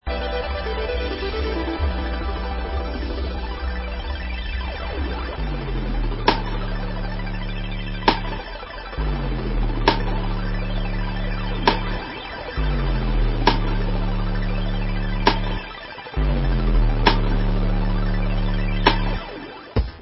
sledovat novinky v oddělení Dance/Breakbeats